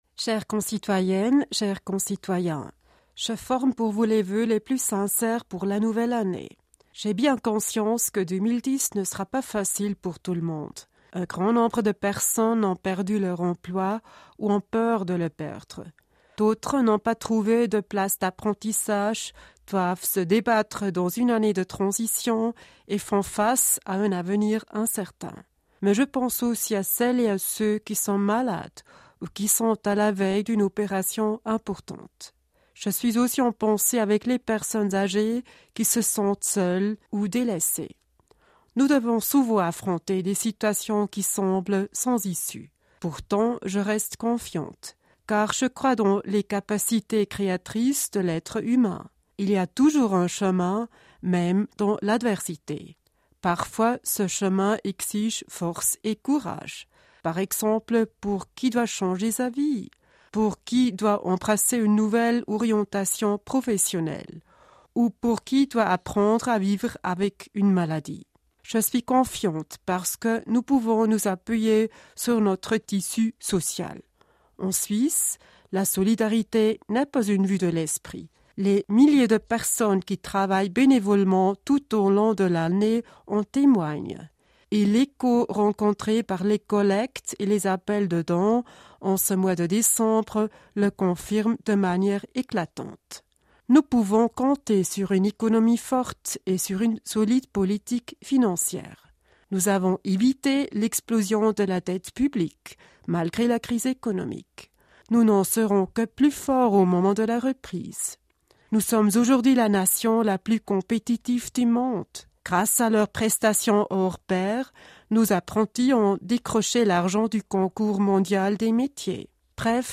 Allocution 2010 de Doris Leuthard